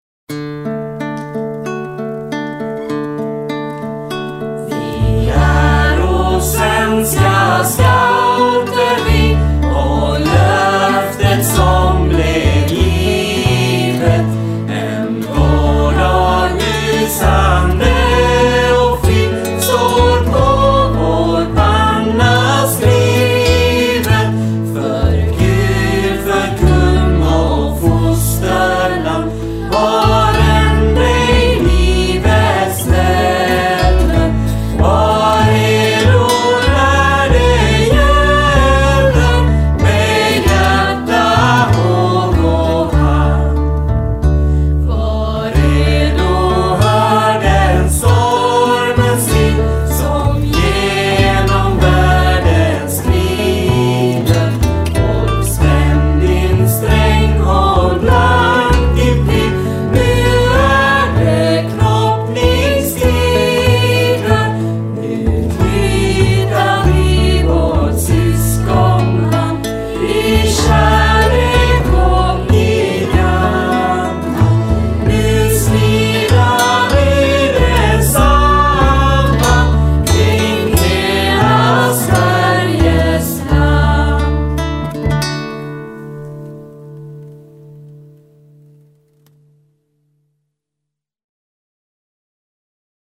Första tonen är den djupaste så för att slippa sjunga falsett bör man ta den så djup som möjligt.